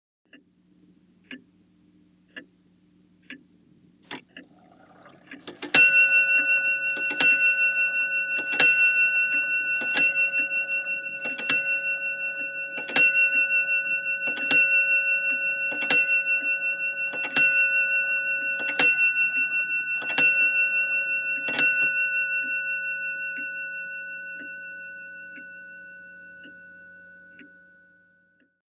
Wall Clock | Sneak On The Lot
CLOCKS WALL CLOCK: INT: Large wall clock ticks and strikes 12 0`clock.